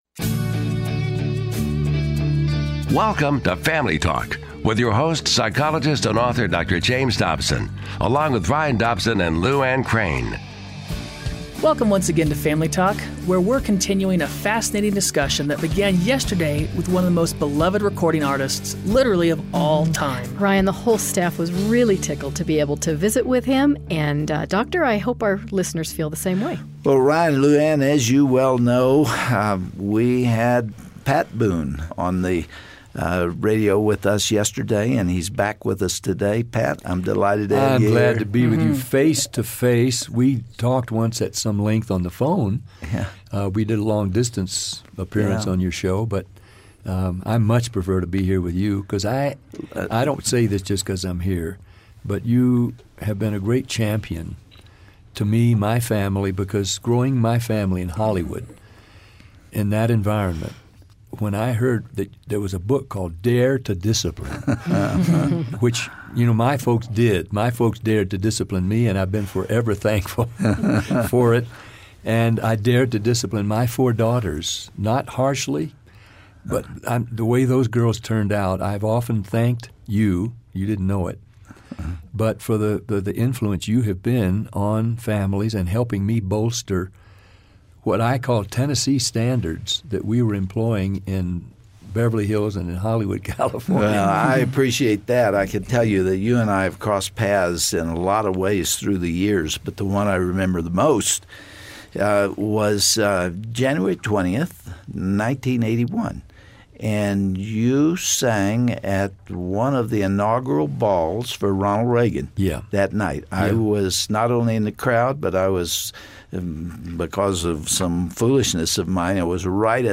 Pat Boone joins Dr. Dobson in the studio again today and discusses the challenges of maintaining a Christian home in the midst of Hollywood pressures. It's a reminder for all of us to parent by our convictions, no matter where we live!